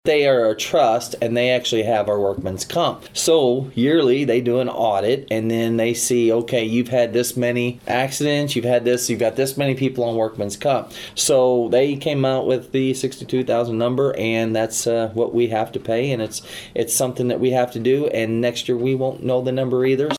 The St. Francois County Commission has approved a payment of over $62,000 to the Missouri Association of Counties for workers' compensation. Associate Commissioner David Kater explains the budget adjustment following an audit of the county’s workers' compensation usage.